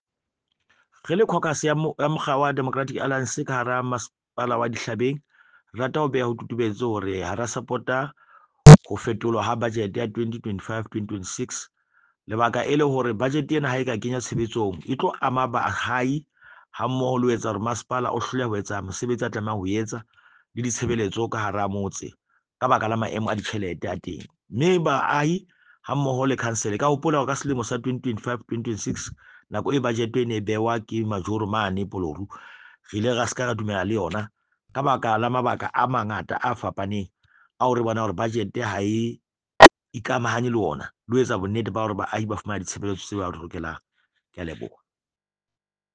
Sesotho soundbite by Cllr Lucas Xaba